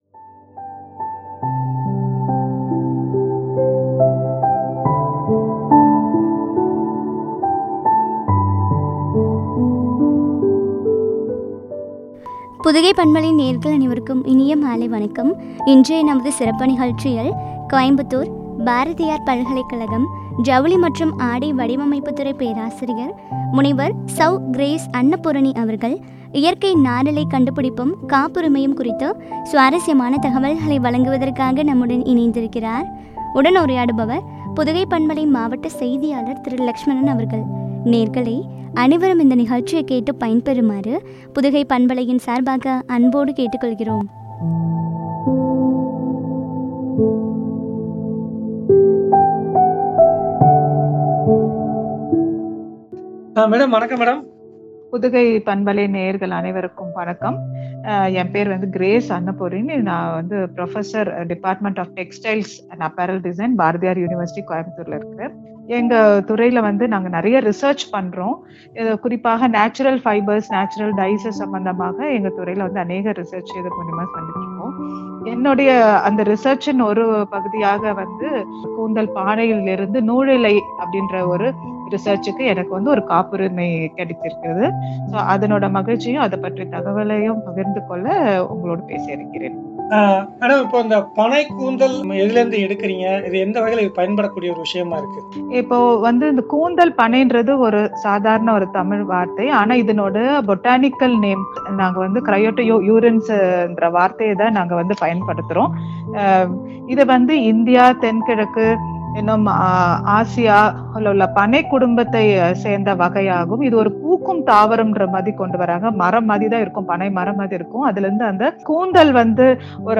காப்புரிமையும் குறித்து வழங்கிய உரையாடல்.